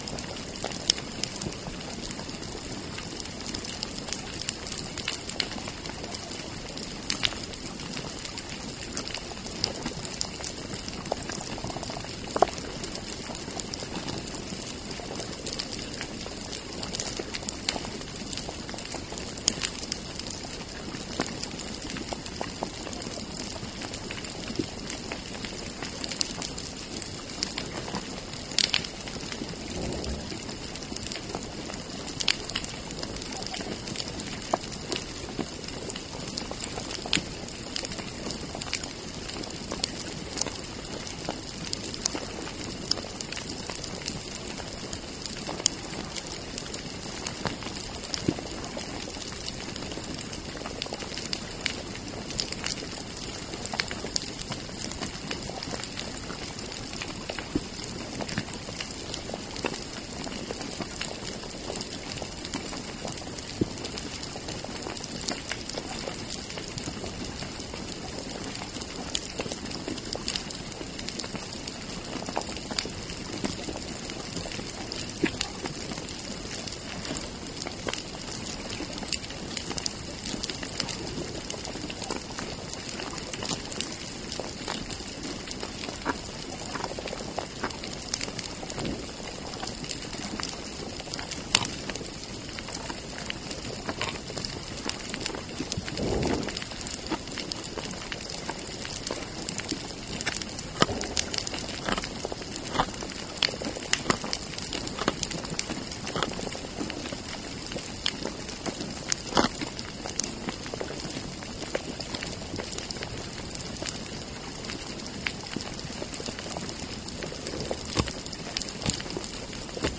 Demonstration soundscapes
biophony